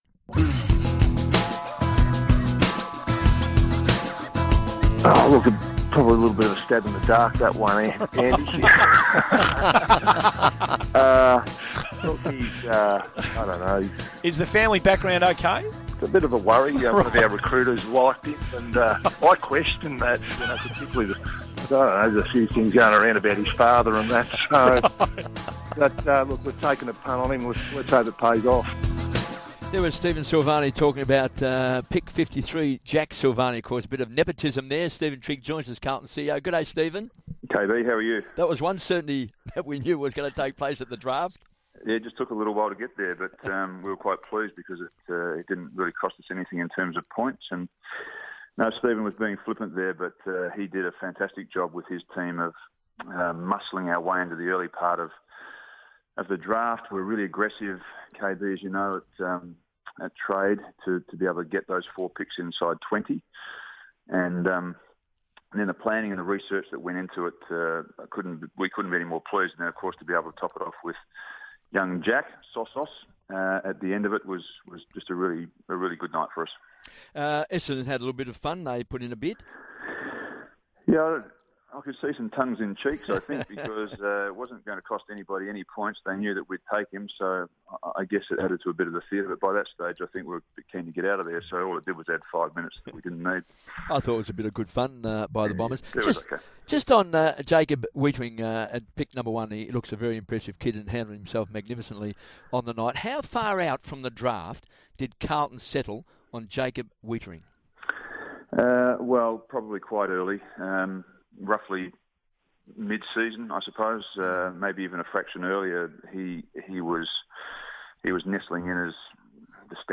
speaks to SEN 1116 program Hungry for Sport after the 2015 national draft.